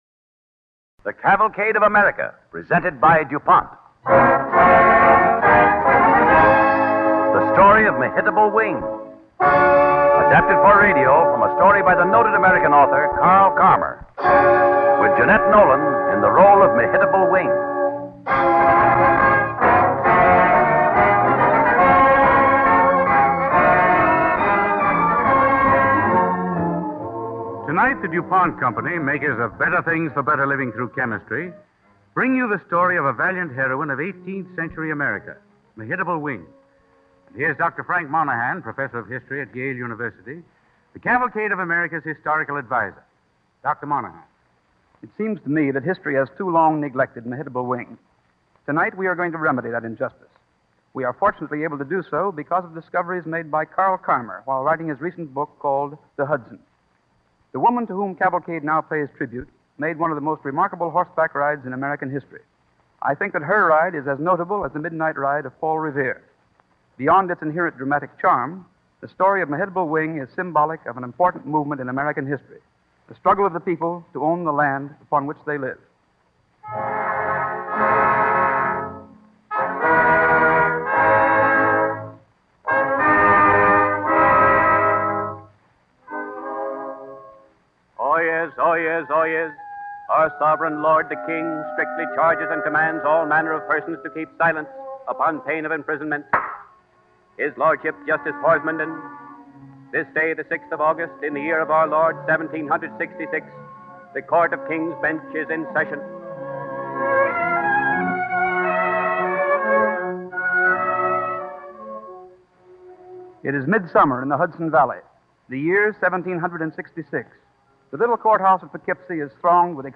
Cavalcade of America Radio Program
With announcer